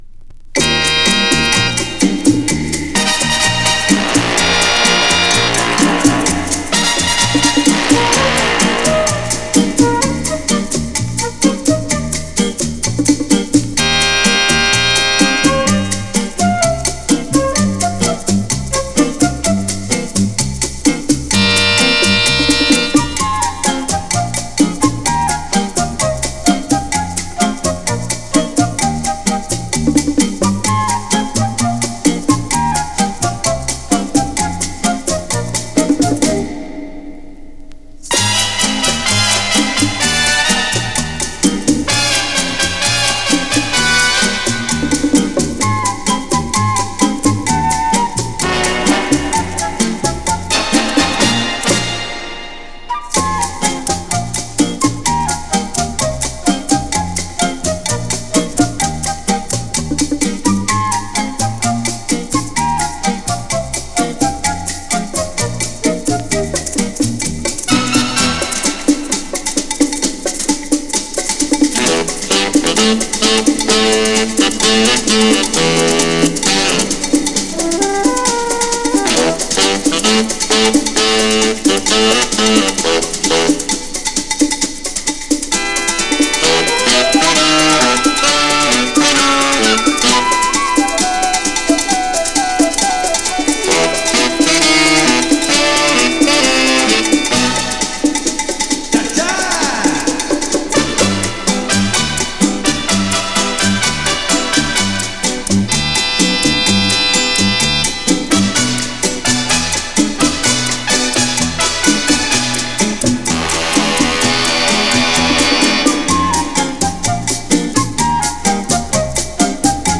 Genre: Jazz, Pop
Style: Easy Listening